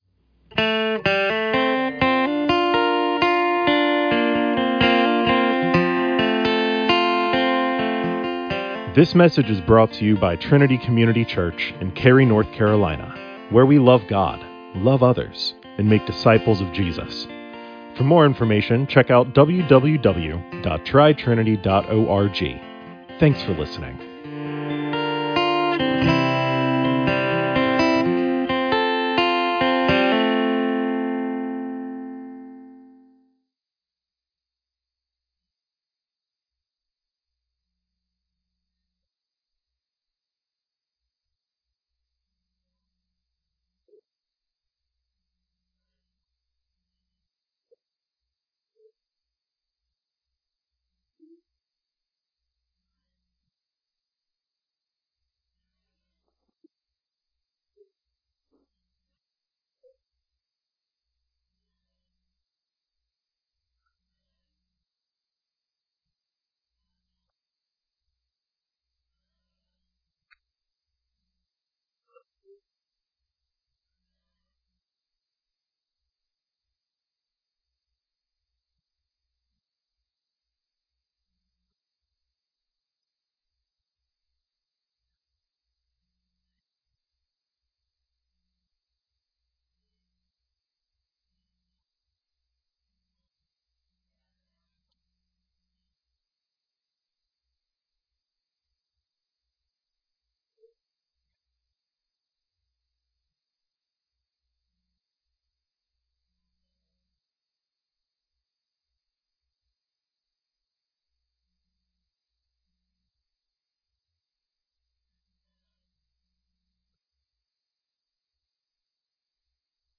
Message from 2 Corinthians 4:16-18